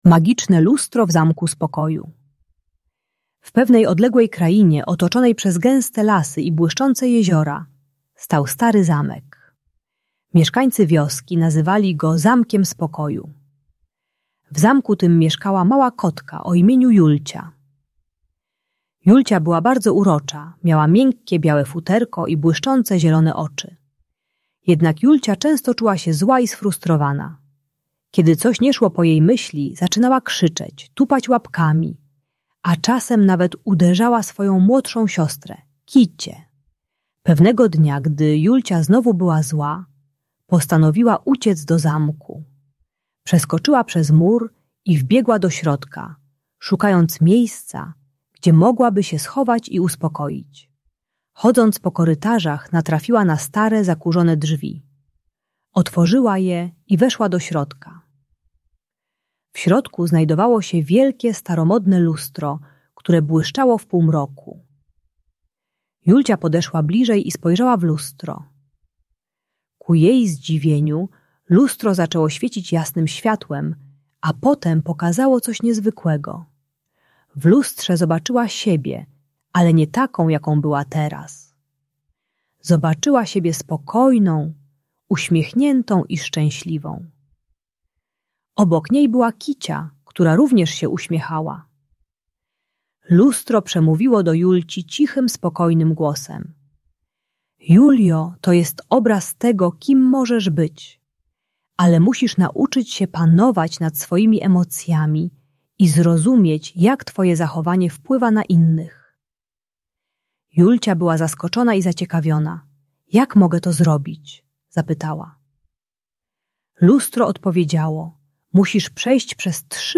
Kotka Julcia uczy się panować nad emocjami poprzez technikę głębokiego oddychania i zrozumienie, jak jej zachowanie wpływa na młodszą siostrę. Audiobajka o złości i agresji u przedszkolaka.